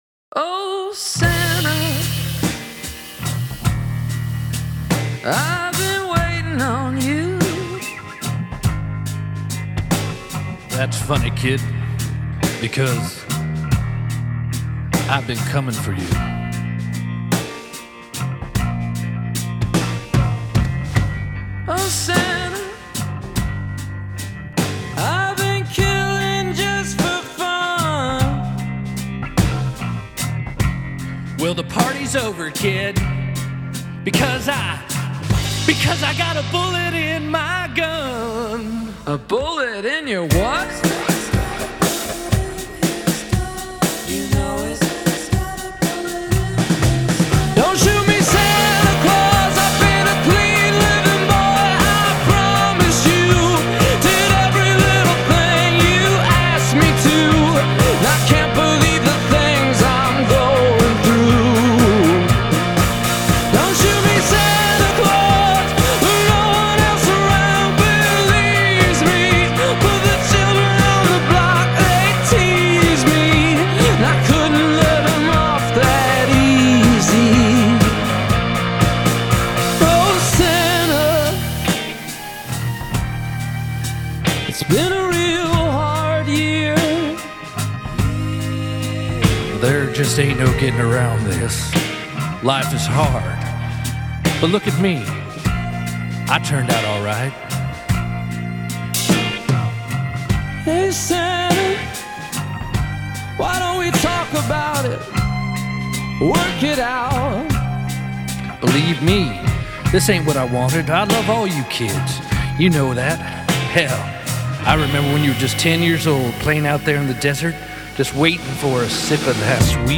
Genre: Indie Rock, Pop-Rock